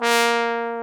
Index of /90_sSampleCDs/Roland L-CDX-03 Disk 2/BRS_Trombone/BRS_Tenor Bone 3